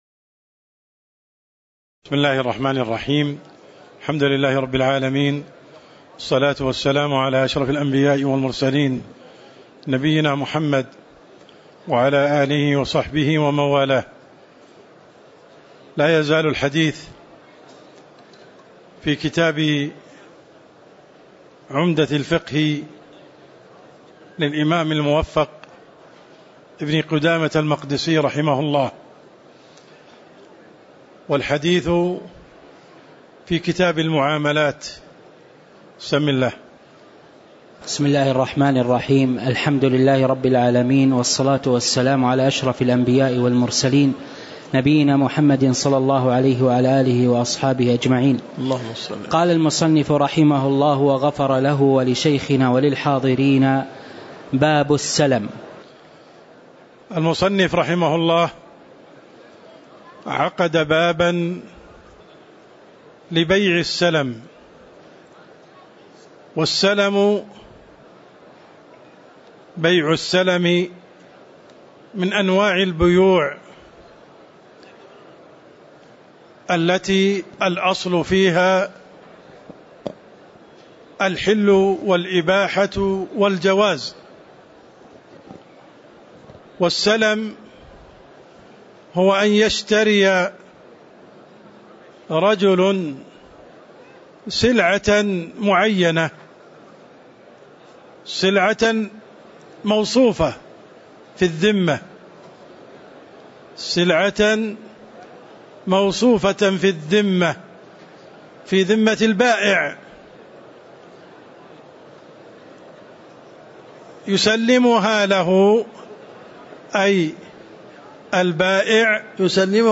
تاريخ النشر ٢٦ جمادى الآخرة ١٤٤١ هـ المكان: المسجد النبوي الشيخ